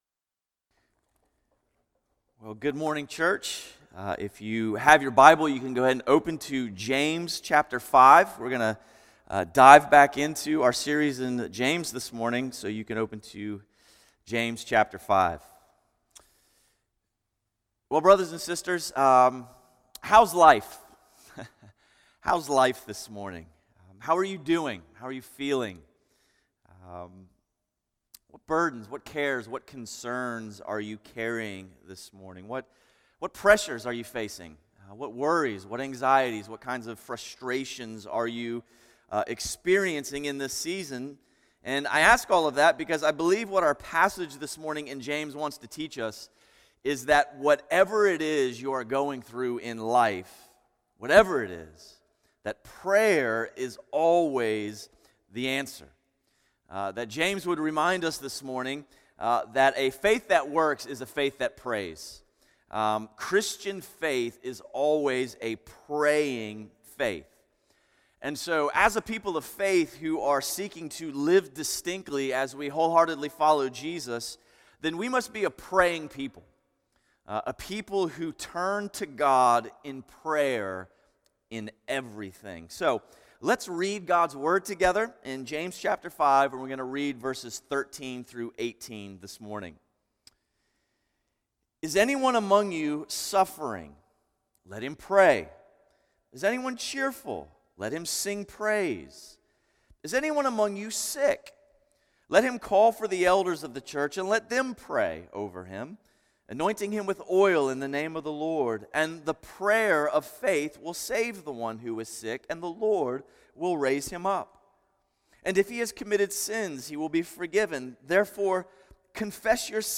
A message from the series "Called Out." Faithfully follow Jesus even when it puts you at odds with the world.